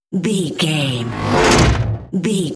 Whoosh electronic fast
Sound Effects
Atonal
Fast
bouncy
bright
futuristic
intense
whoosh
sci fi